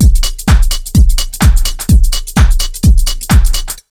127BEAT4 1-R.wav